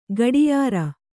♪ gaḍiyāra